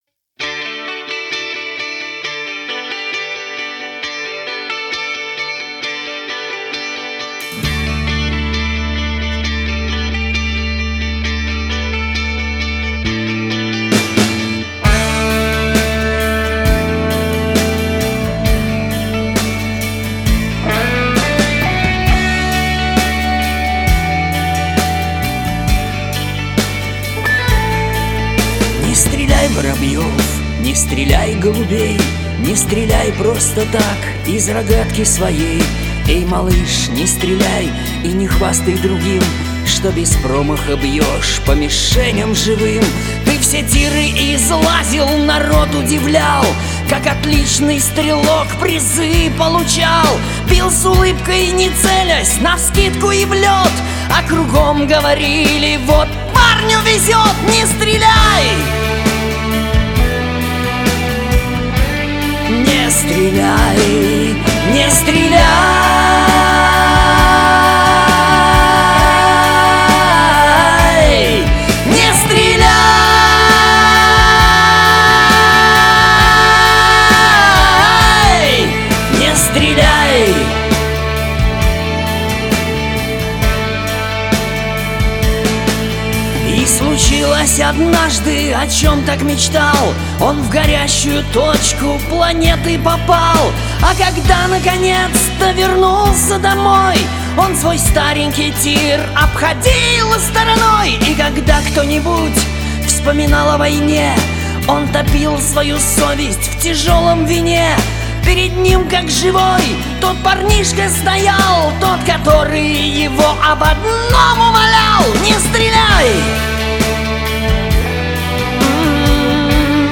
Комментарий соперника: Попробуем?Только я без ансамбля...